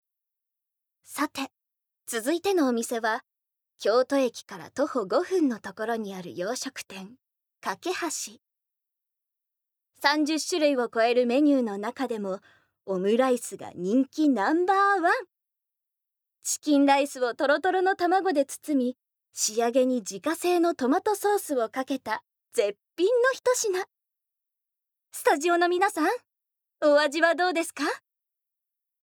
ボイスサンプル